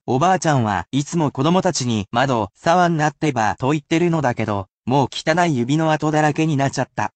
[casual speech]